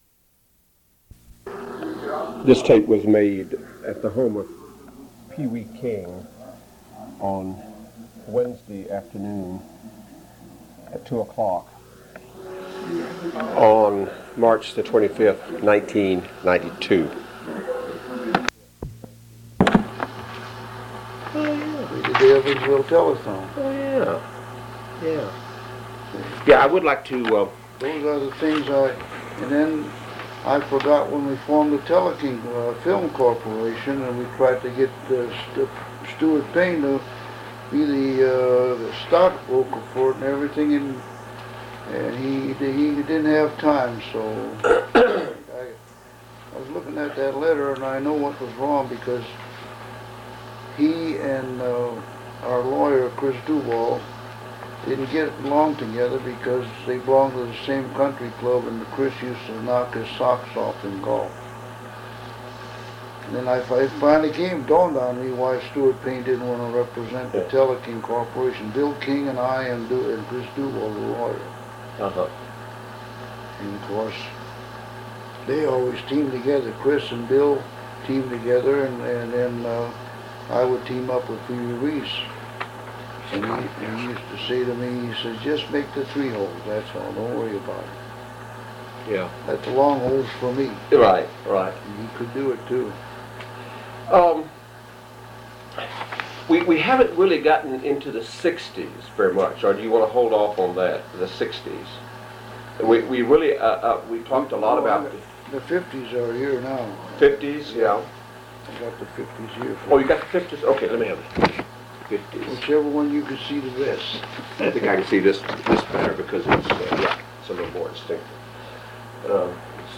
Oral History Interview with Pee Wee King